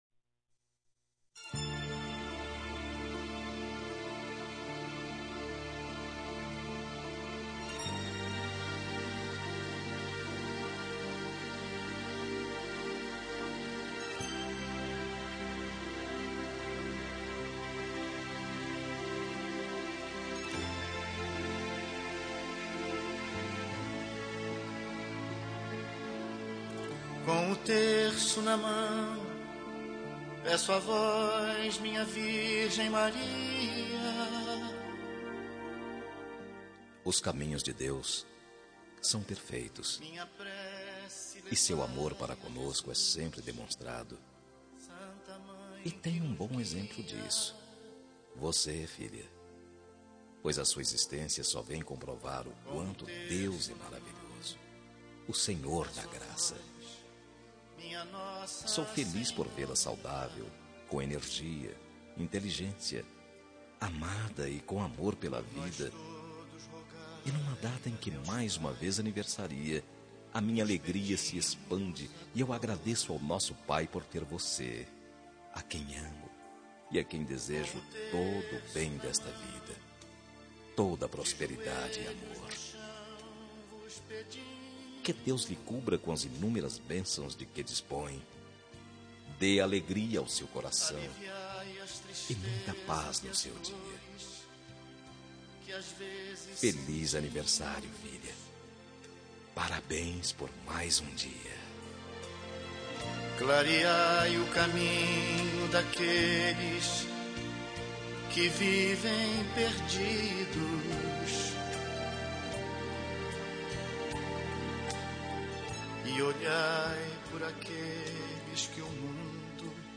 Telemensagem de Aniversário de Filha – Voz Masculina – Cód: 1801 – Religiosa